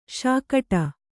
♪ śakaṭa